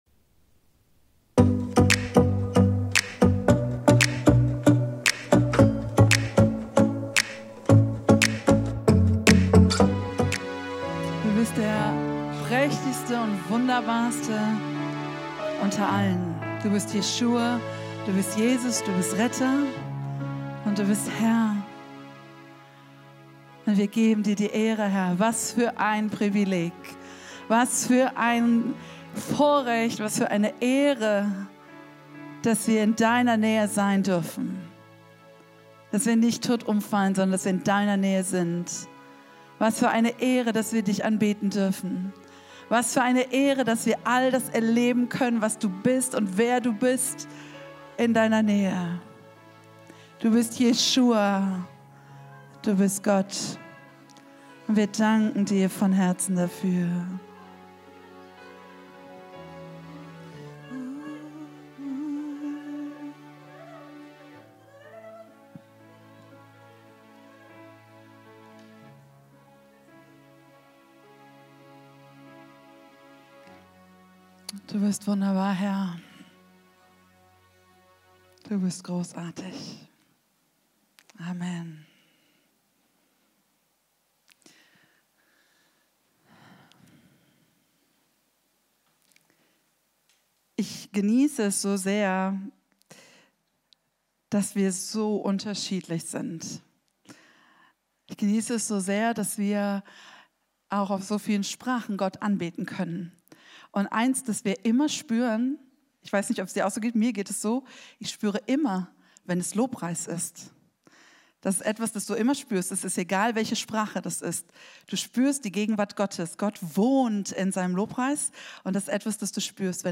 Live-Gottesdienst aus der Life Kirche Langenfeld.
Kategorie: Sonntaggottesdienst Predigtserie: Advent - eine Ankunft, die verwandelt